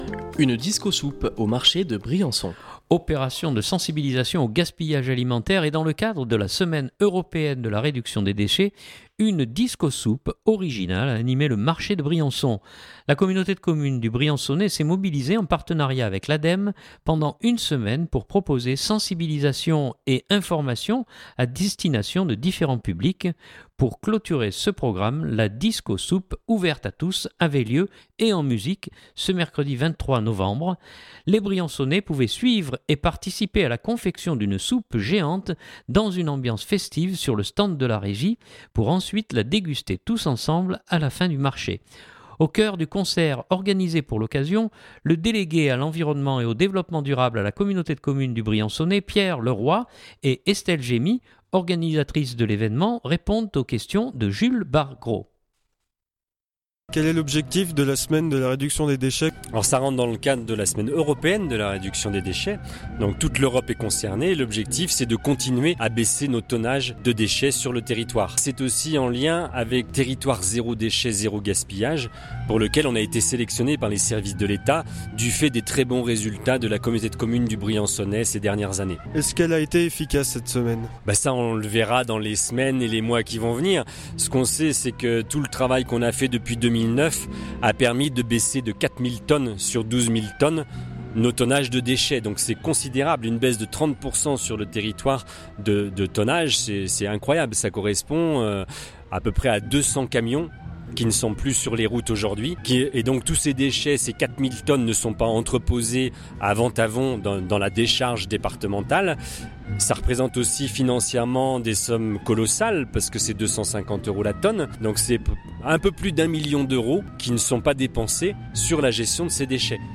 Les Briançonnais pouvaient suivre et participer à la confection d’une soupe géante dans une ambiance festive sur le stand de la régie, pour ensuite la déguster tous ensemble à la fin du marché.